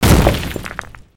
debris1.ogg